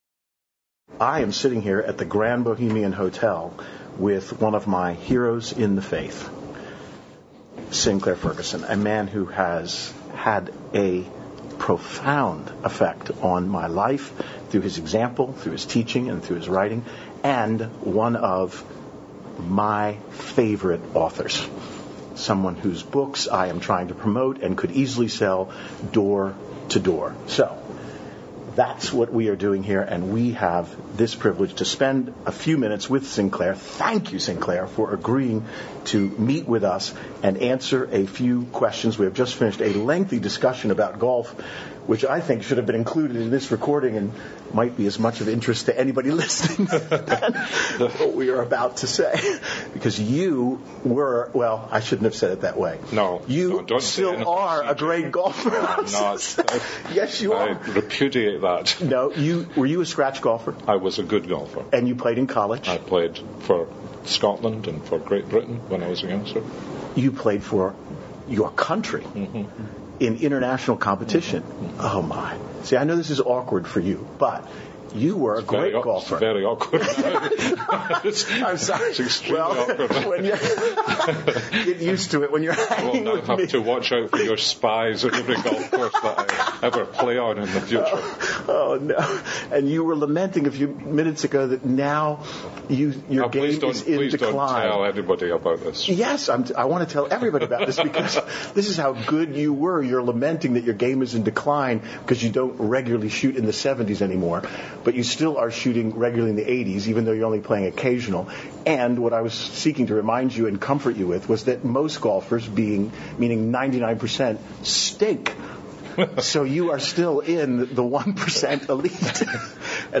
And he has that accent I would pay a large amount of cash to obtain.